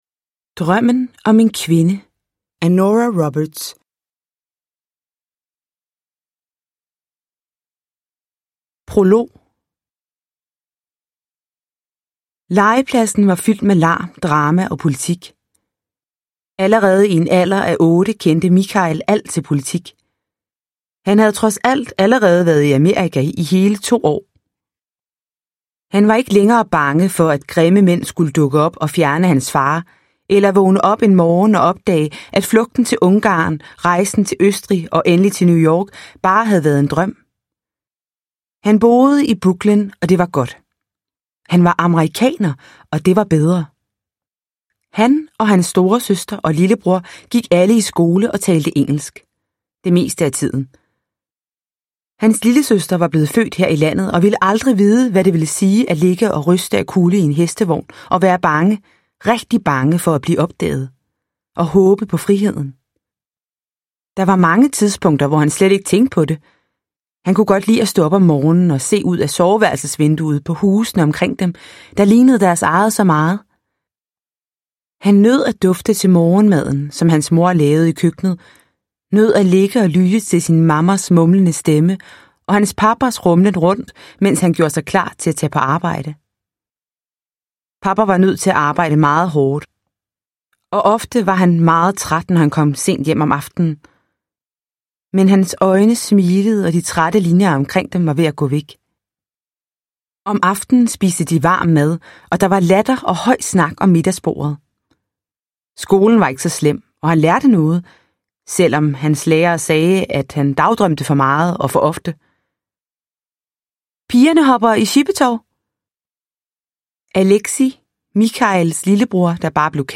Drømmen om en kvinde – Ljudbok – Laddas ner